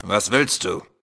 Kategorie:Fallout: Audiodialoge Du kannst diese Datei nicht überschreiben.